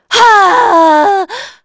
Veronica-Vox_Casting4.wav